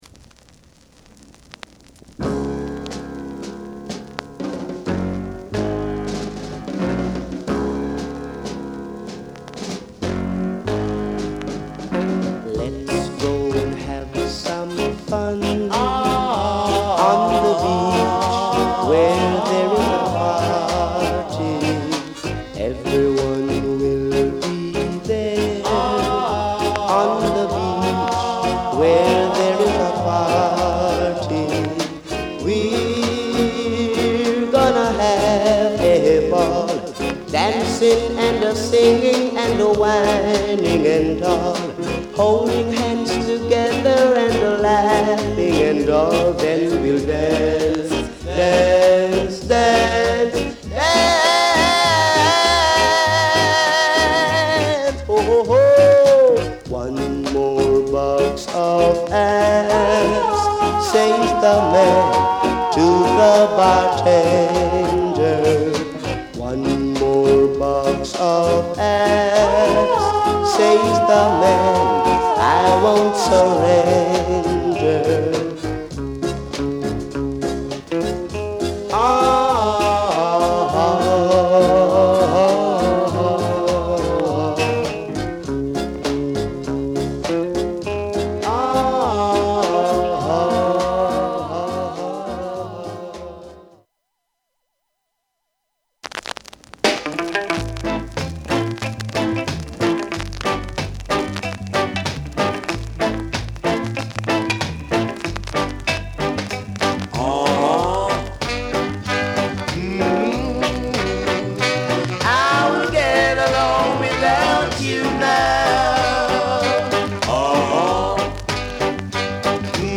Genre: Rocksteady